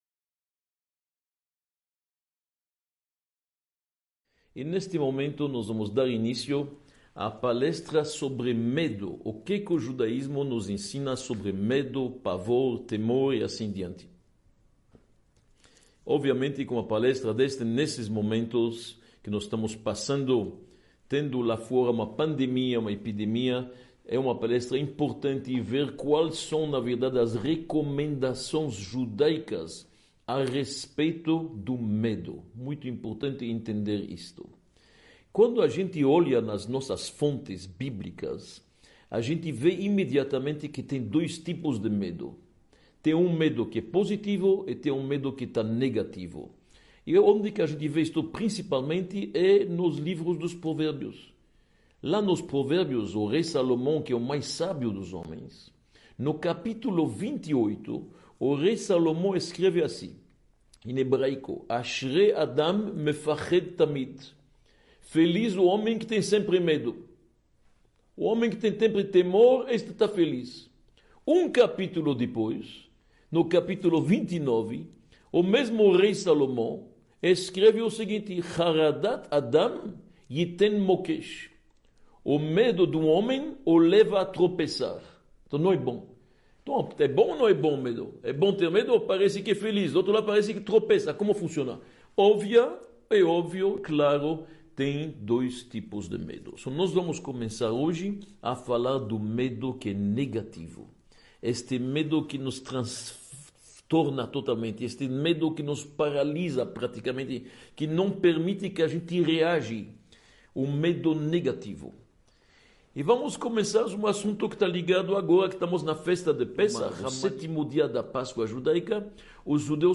24 – Medo: como lidar com ele na pandemia | Módulo I – Aula 24 | Manual Judaico